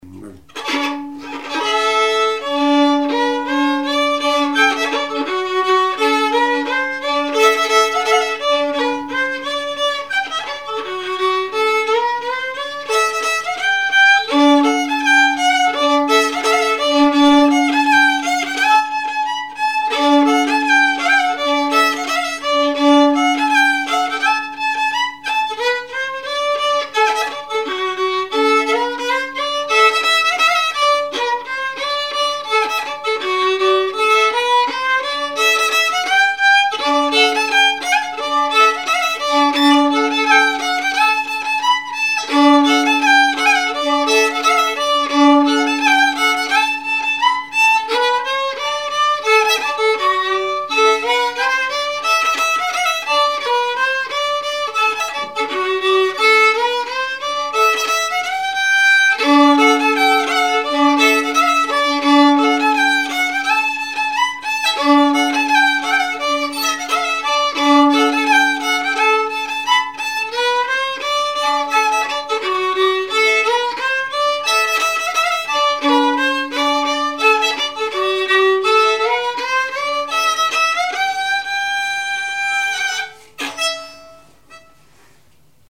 danse : paskovia
Témoignages et chansons
Pièce musicale inédite